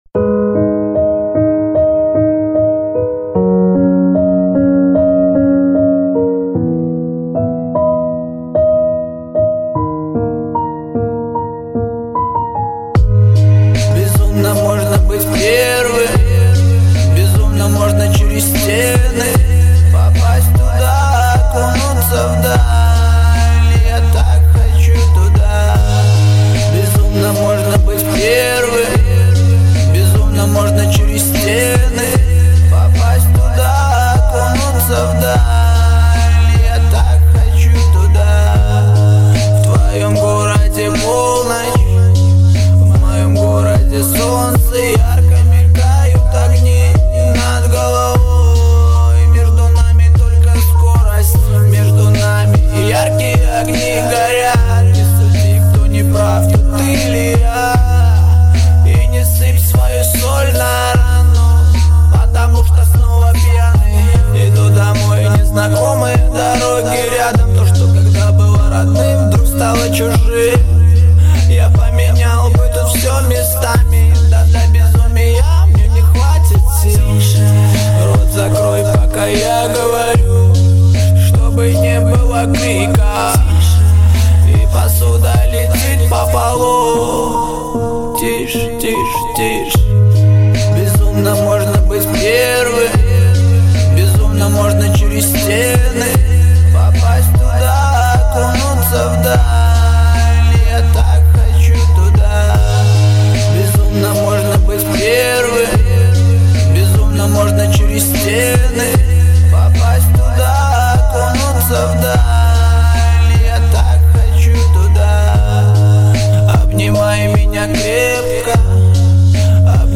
БАСЫ в МАШИНУ